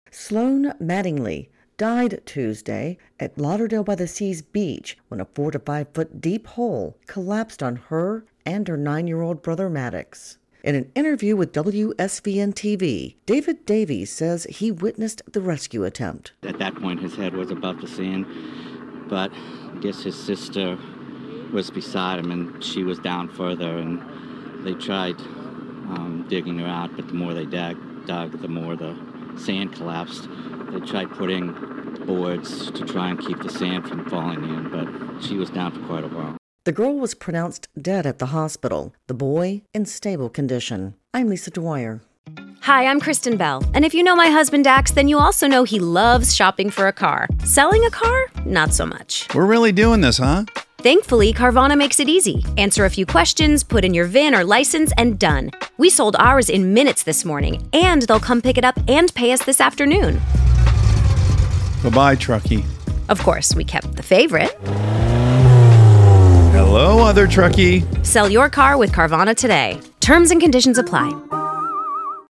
SOUND COURTESY: WSVN ((attribution is in package)) Share Facebook X Subscribe Next A sand hole collapse in Florida killed a child.